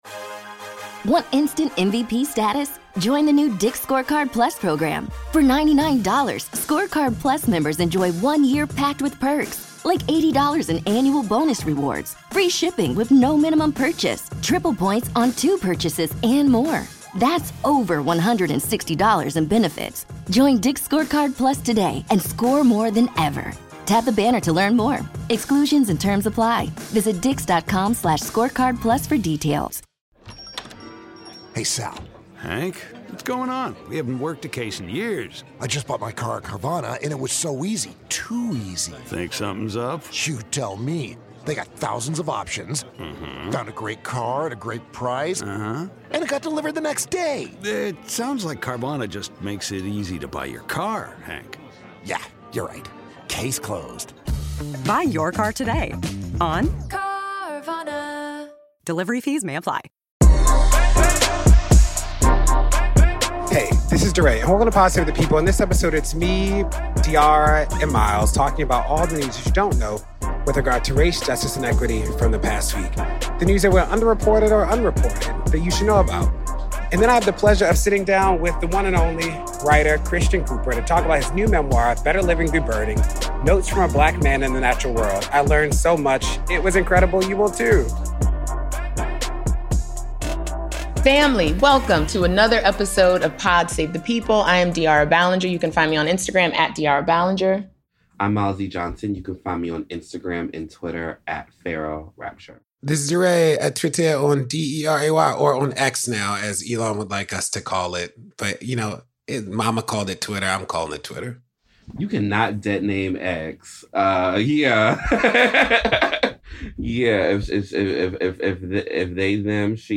DeRay interviews author Christian Cooper to about his new book Better Living Through Birding: Notes from a Black Man in the Natural World.